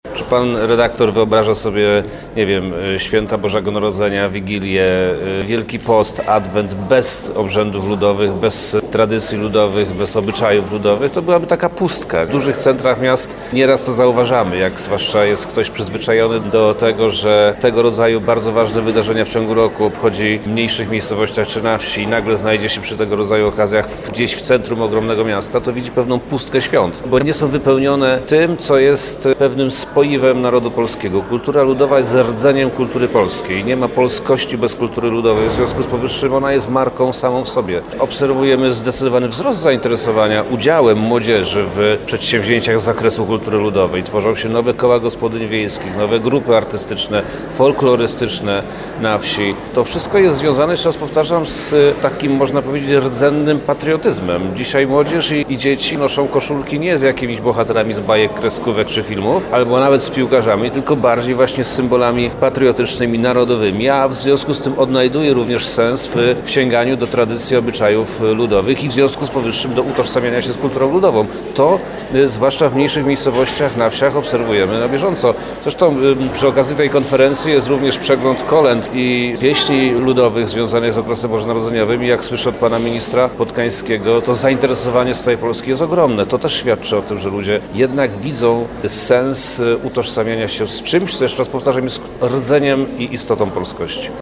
Otwierając spotkanie wojewoda lubelski Przemysław Czarnek – podkreślił, że „kultura ludowa – mająca tak bardzo silne tradycje w naszym regionie jest marką samą w sobie.